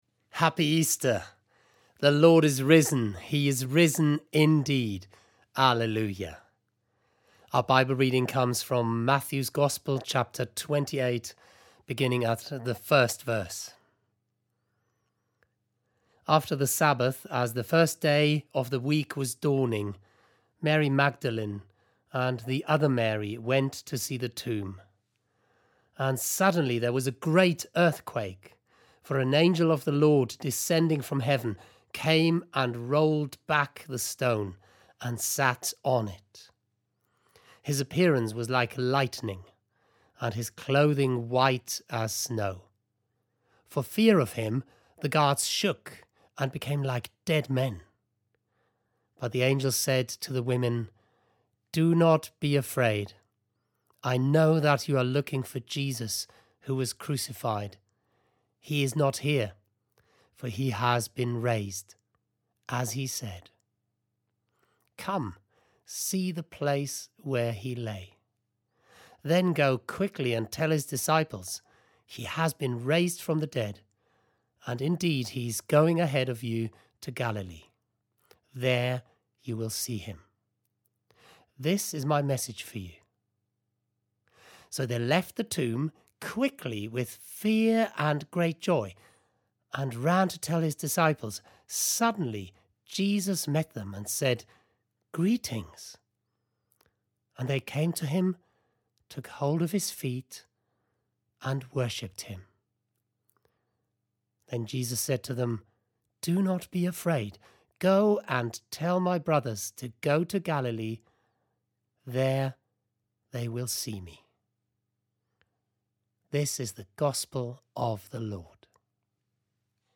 The reading is included in the service.